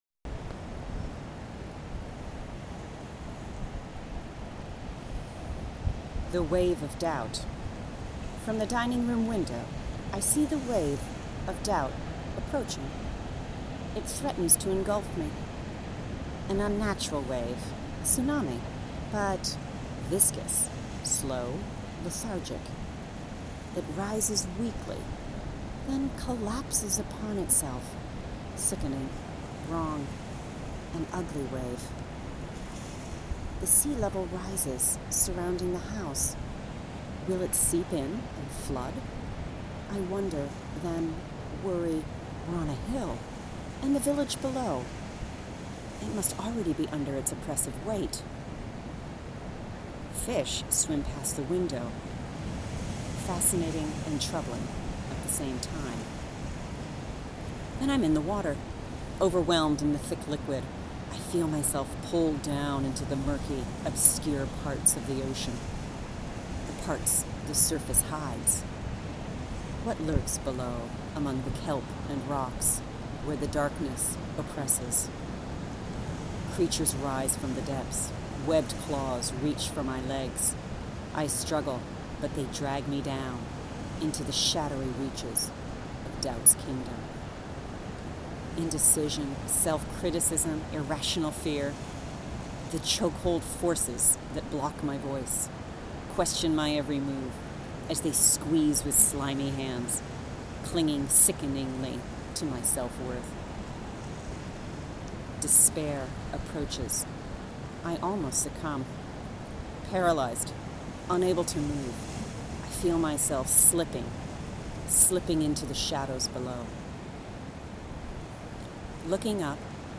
Enjoy the audio version of the poem too that I recorded by the beautiful Bay where I visualized much of the imagery in the poem.